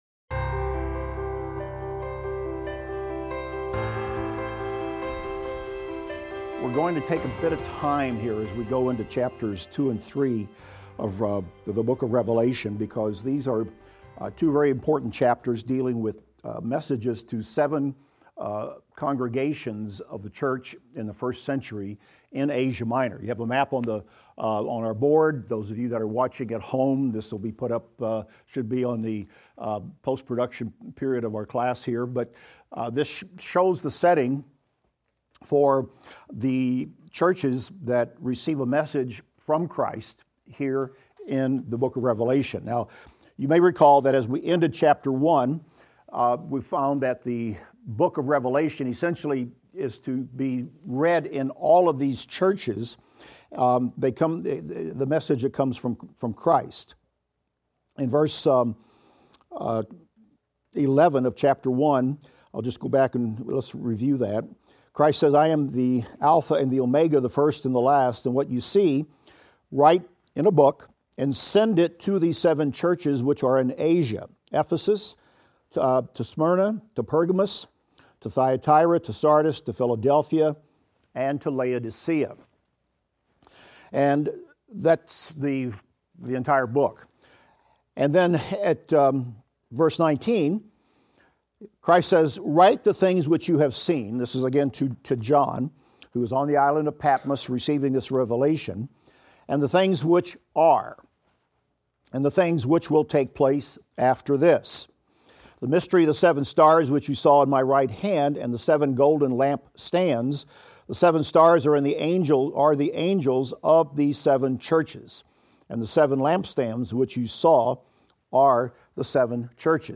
Revelation - Lecture 27 - audio.mp3